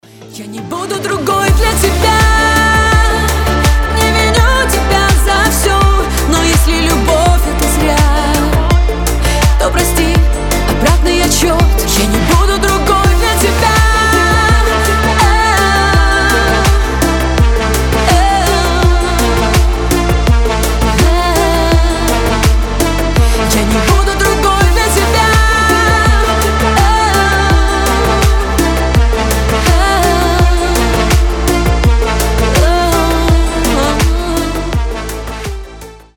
поп , танцевальные
женский голос